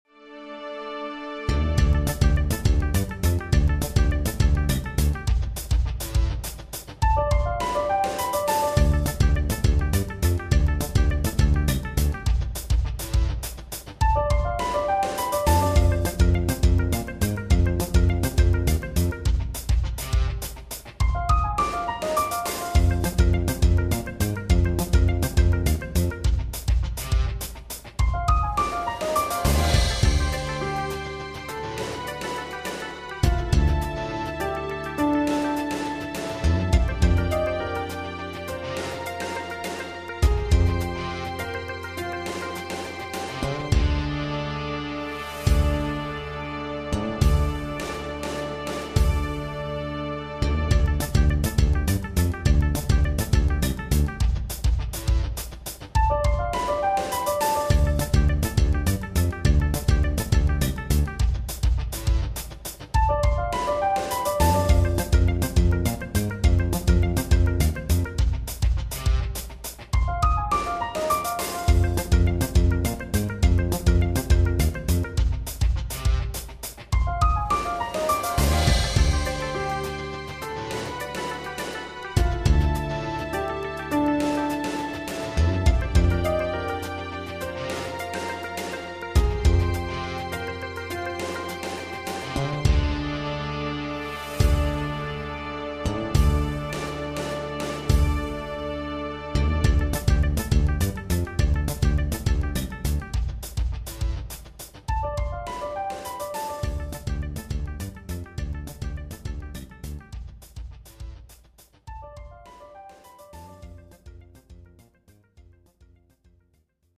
エフェクトでバスドラムを強調するのは割とうまくいったかな。